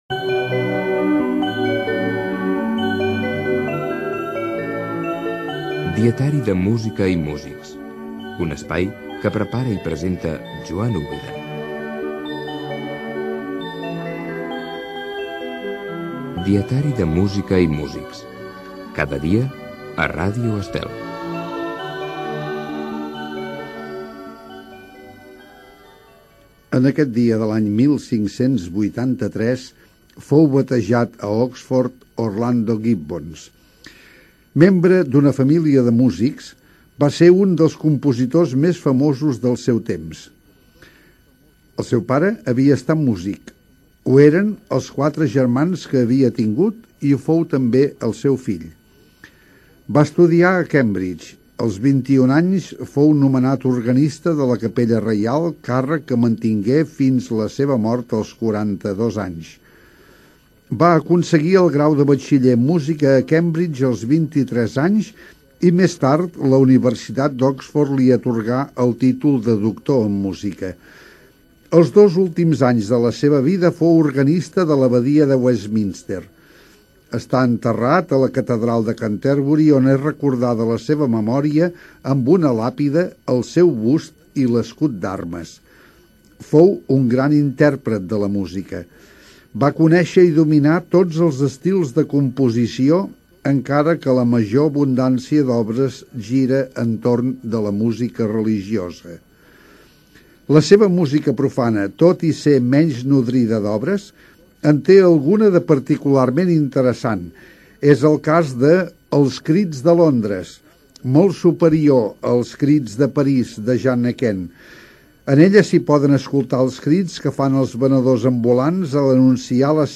Careta del programa, descripció de la vida d'Orlando Gibbons. Acaba amb una mostra de la peça musical "Fum, fum. fum" interpretat per l'Escola Cantorum Parroquial de Sant Vicenç de Sarrià"
Musical